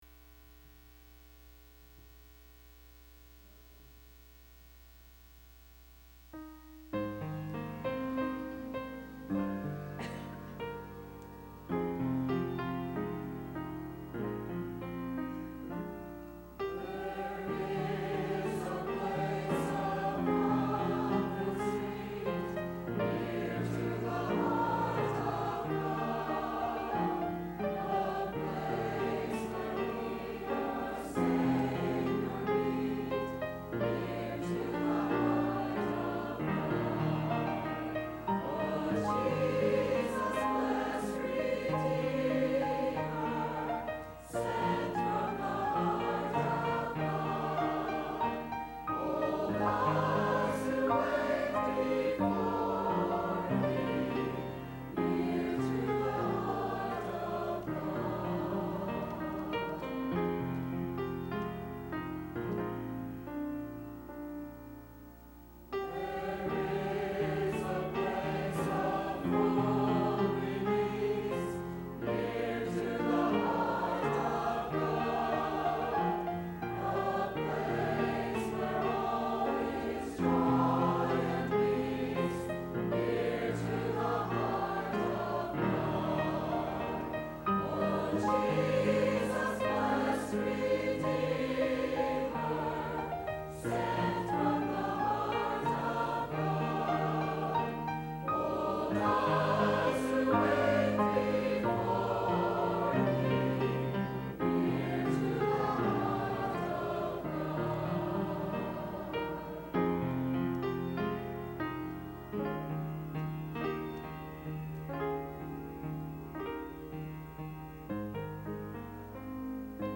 “Peace Medley”  by Faith Baptist Choir
choir-peace-medley.mp3